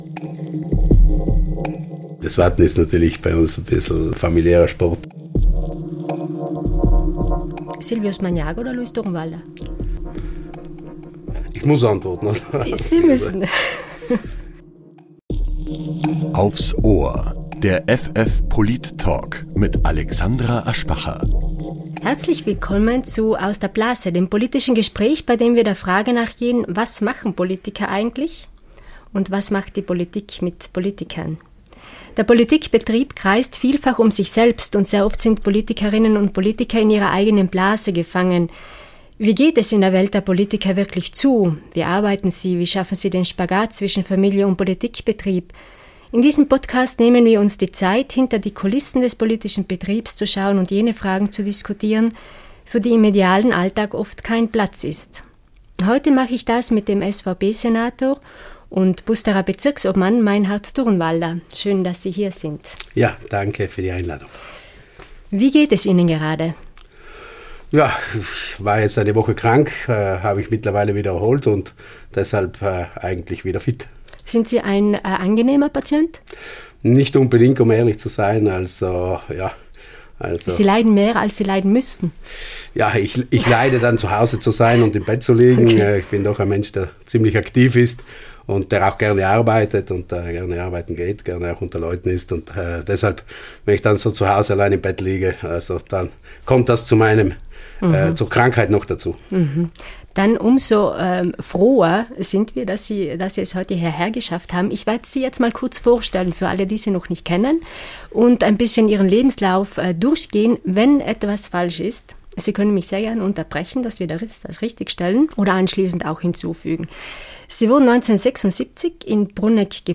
Der Polit-Talk
Gast in Folge 2 ist SVP-Senator Meinhard Durnwalder